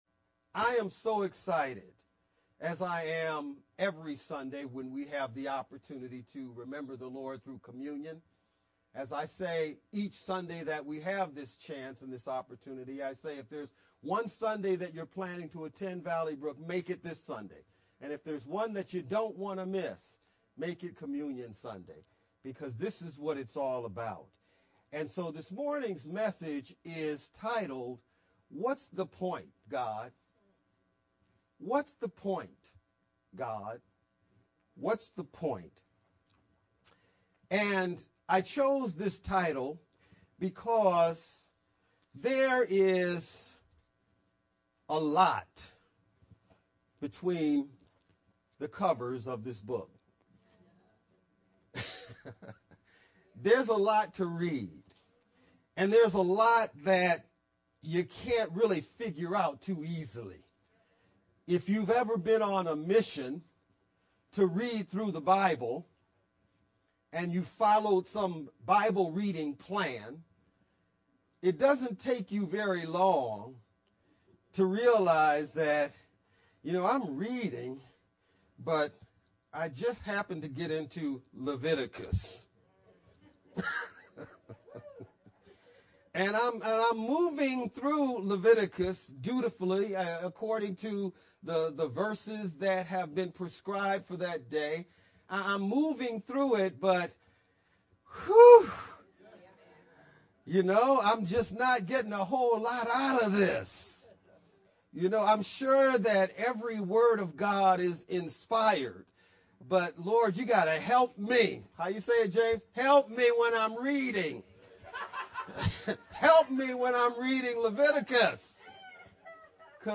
VBCC-Sermon-4-23-edited-sermon-only-Mp3-CD.mp3